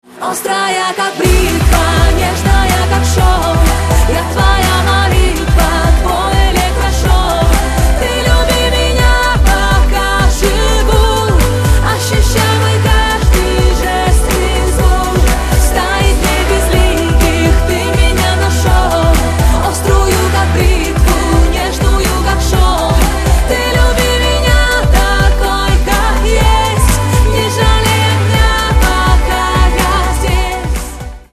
поп
громкие
красивые